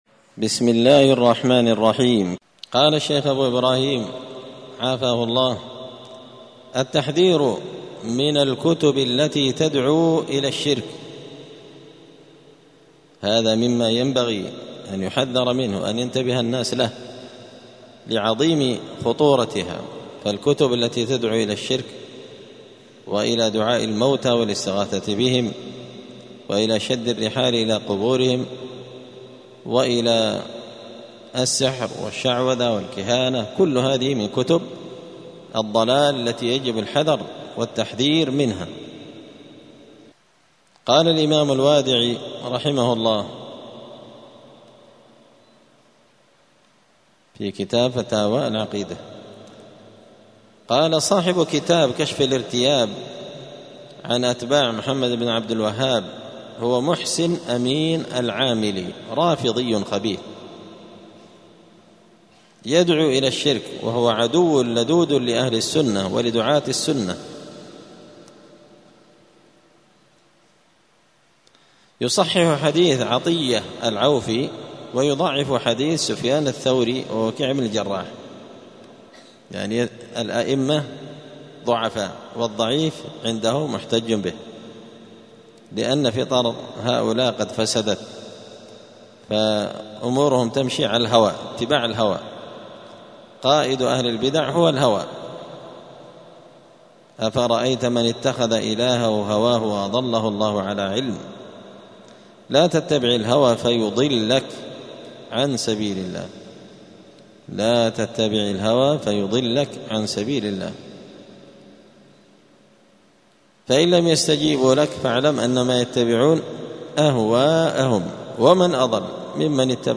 دار الحديث السلفية بمسجد الفرقان بقشن المهرة اليمن
الجمعة 29 ذو الحجة 1445 هــــ | الدروس، الفواكه الجنية من الآثار السلفية، دروس الآداب | شارك بتعليقك | 53 المشاهدات